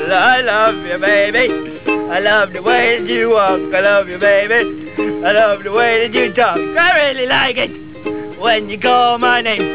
Brak Radio Interview
(tunes ukelele)
(stops playing, everybody laughs)